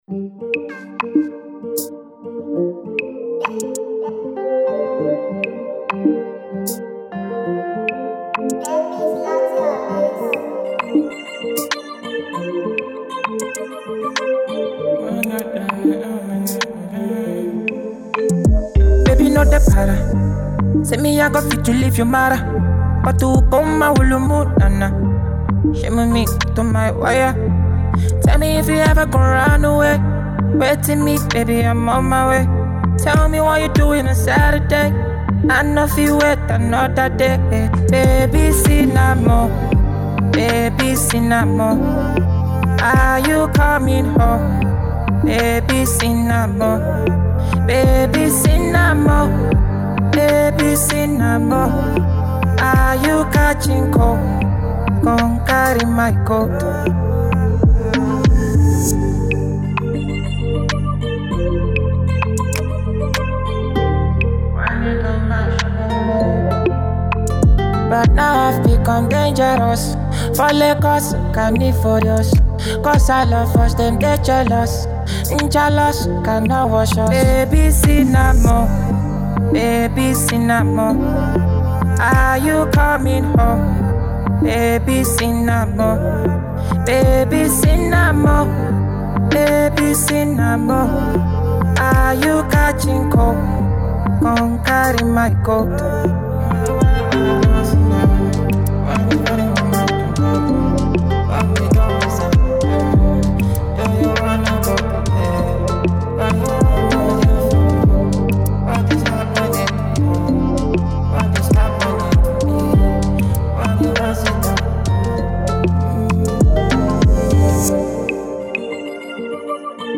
Afro Sounds